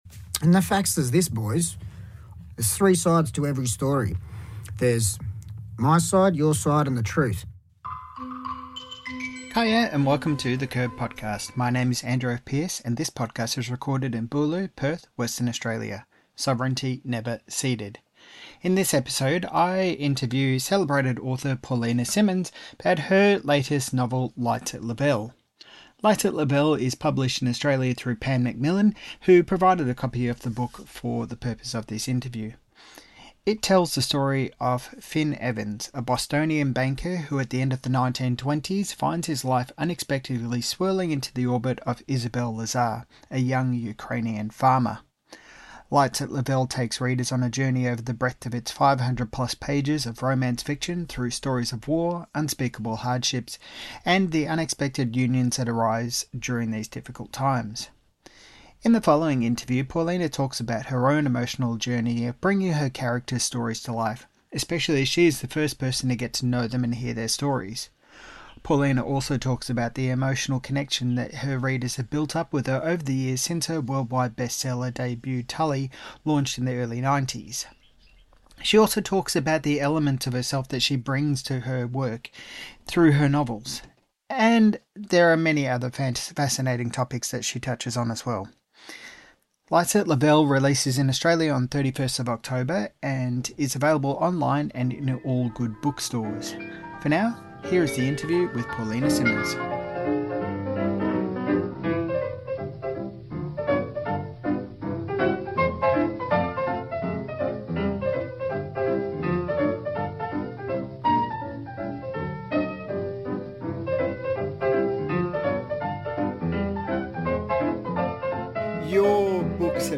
Paullina Simons Talks About Her New Novel Light at Lavelle and the Emotionality of Her Characters in This Interview - The Curb